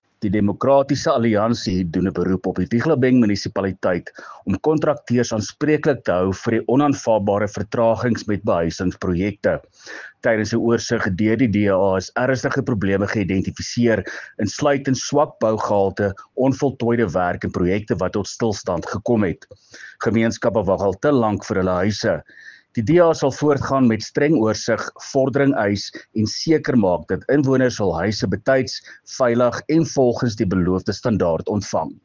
Afrikaans soundbite by Cllr Willie Theunissen